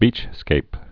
(bēchskāp)